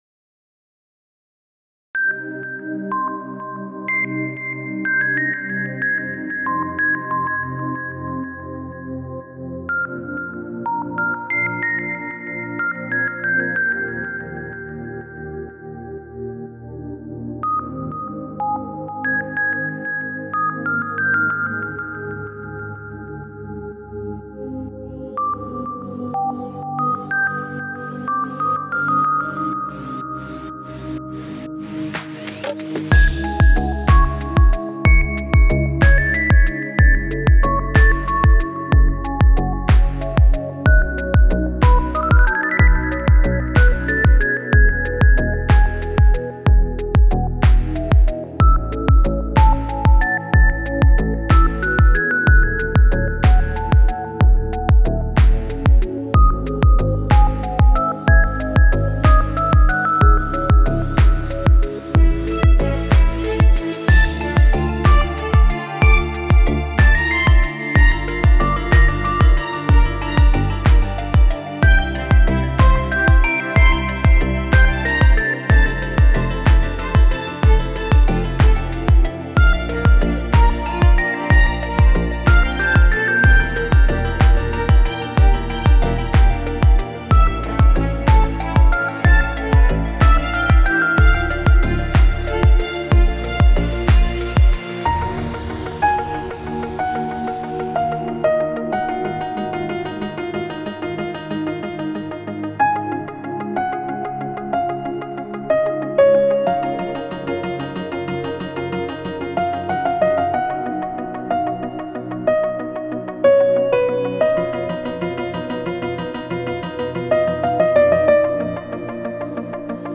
Категория: Спокойная музыка
спокойные треки